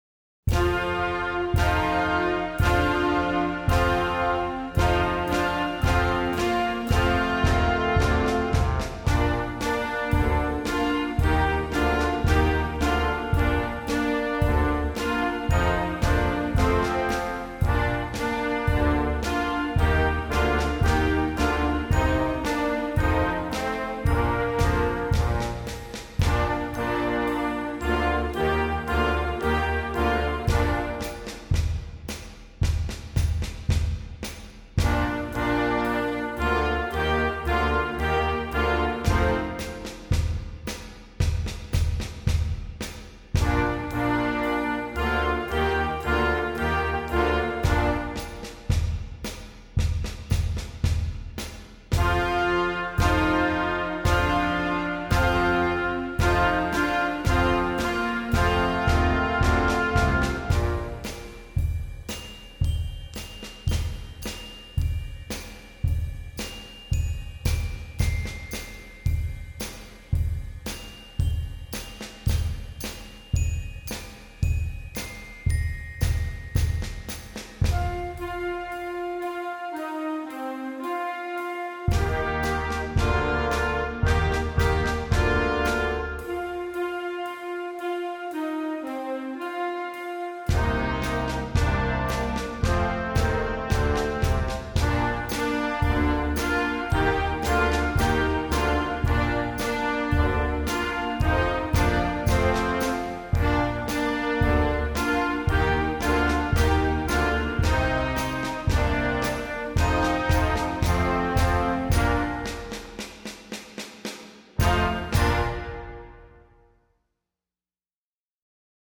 Gattung: Jugendblasmusik
Besetzung: Blasorchester